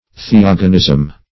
\The*og"o*nism\
theogonism.mp3